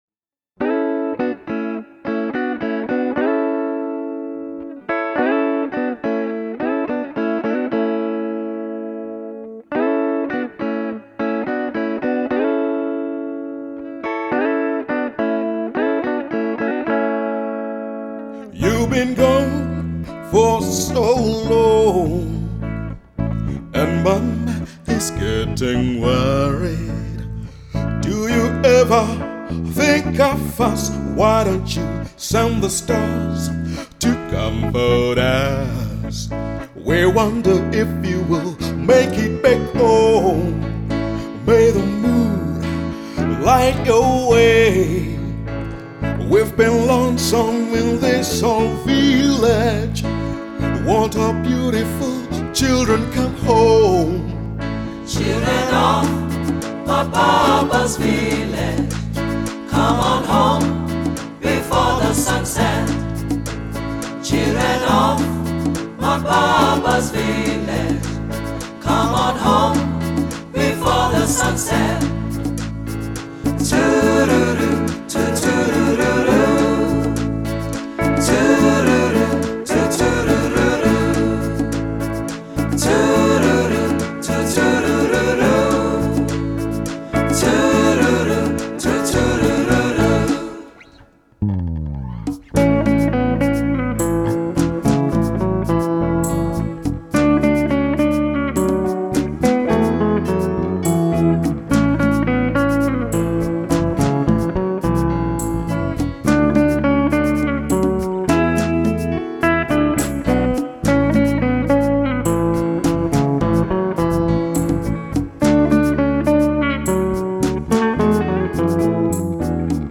up tempo and exuberant music